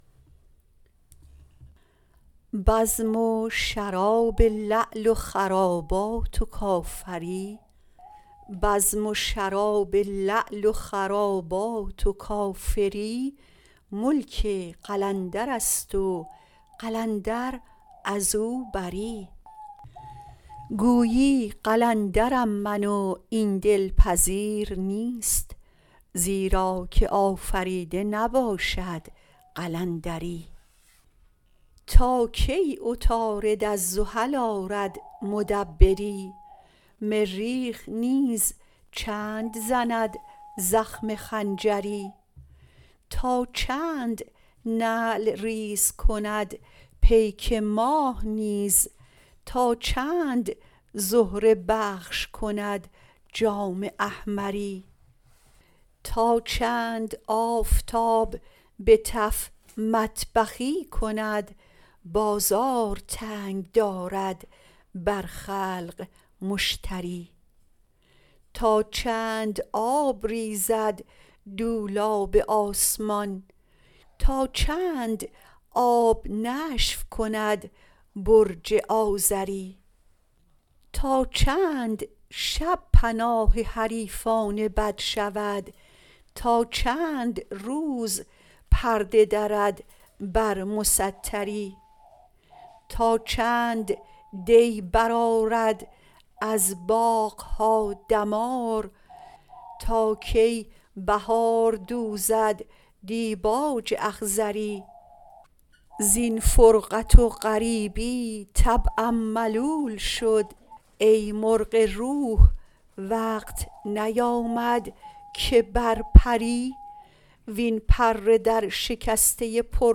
مولانا » دیوان شمس » غزلیات » غزل شمارهٔ ۳۰۰۴ با خوانش